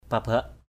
/pa-baʔ/